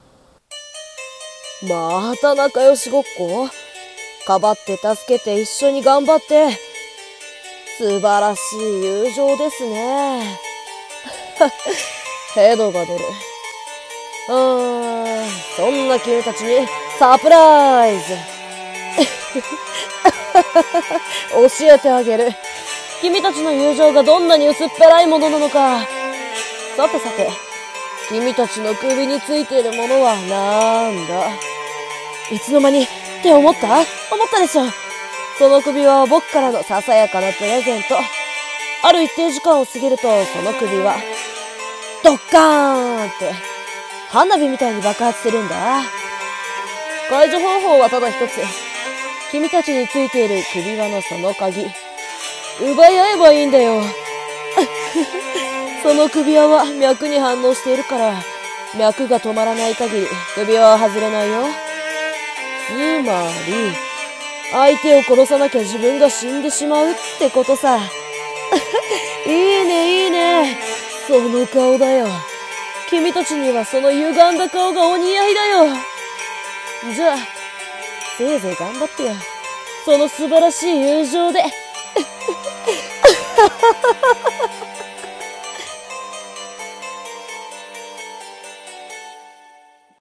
【一人声劇】教えてあげる【悪役】